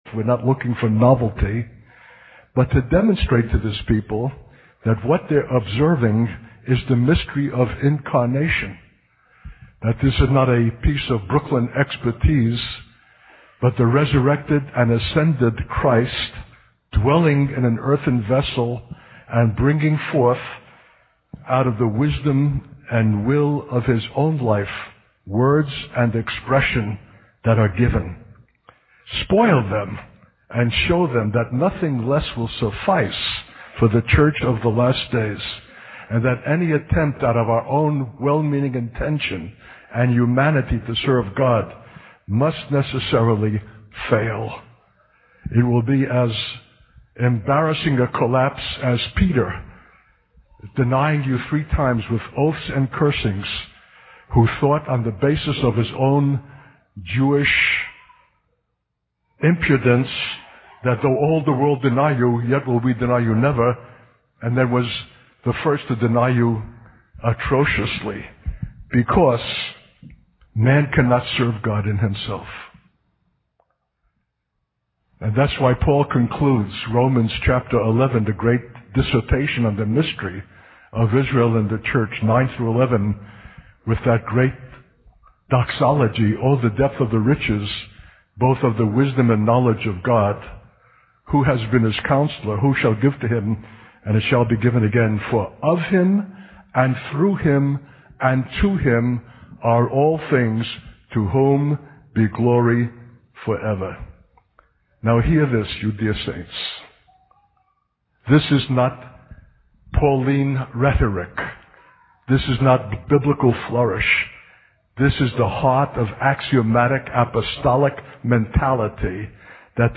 A 2004 message.